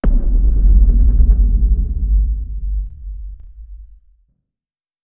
Low End 09.wav